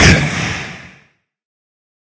minecraft / sounds / mob / wither / hurt4.ogg
hurt4.ogg